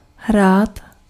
Ääntäminen
Synonyymit představovat Ääntäminen : IPA: [ɦraːt] Haettu sana löytyi näillä lähdekielillä: tšekki Käännös Ääninäyte Verbit 1. play US Esimerkit Pojďme hrát Monopoly.